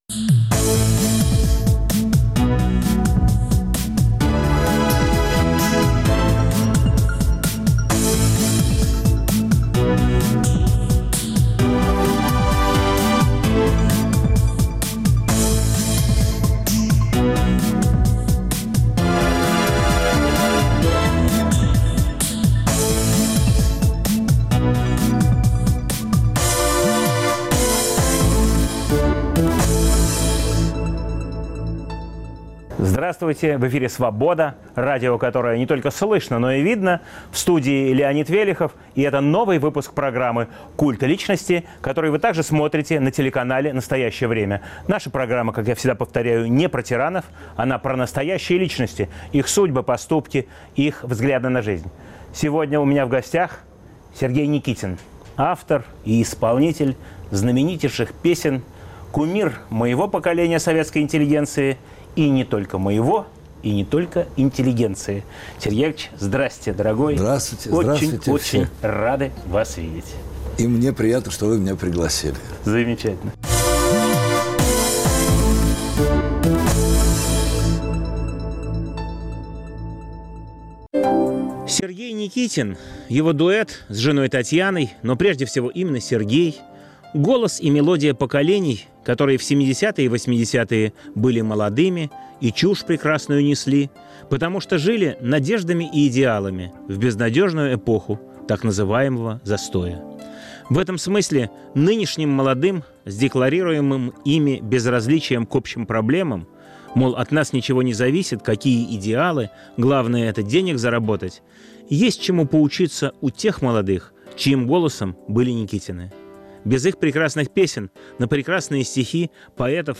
В студии - композитор, автор и исполнитель культовых песен Сергей Никитин.